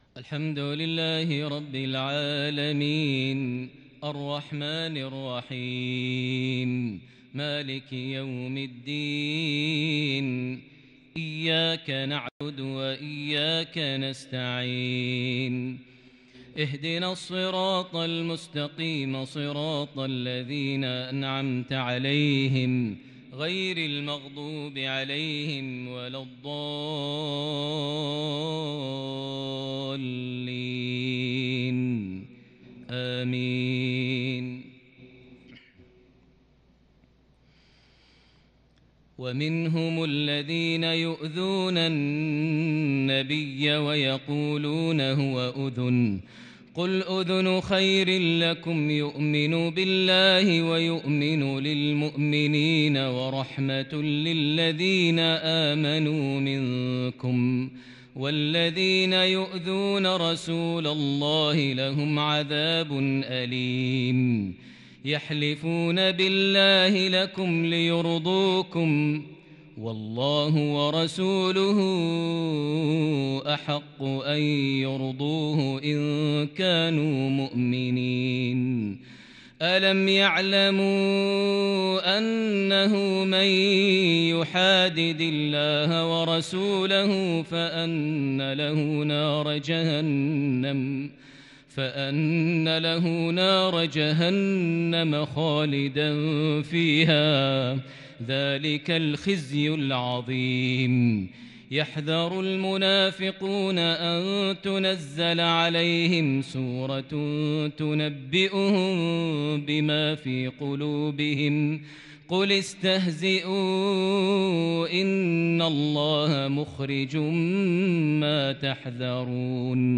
صلاة العشاء من سورة التوبة |الأربعاء 25 ذي الحجة 1442هـ | lsha 4-8-2021 prayer from Surah At-Tawba > 1442 🕋 > الفروض - تلاوات الحرمين